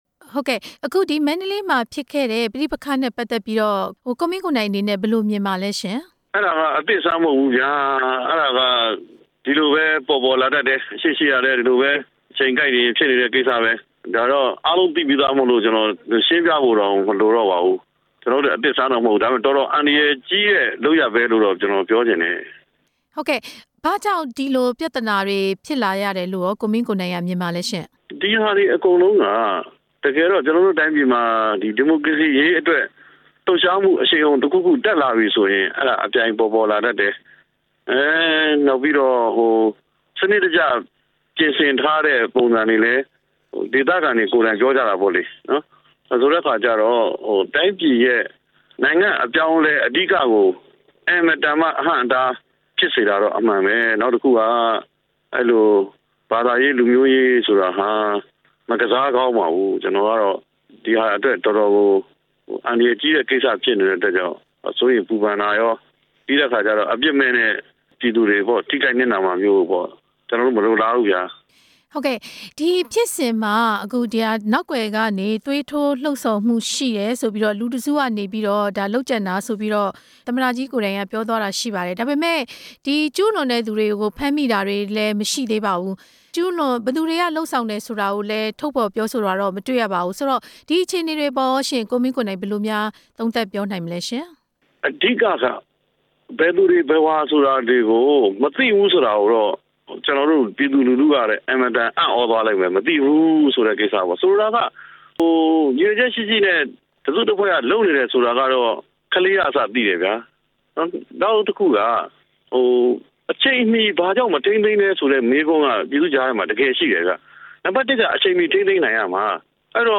ကိုမင်းကိုနိုင်နဲ့ မေးမြန်းချက်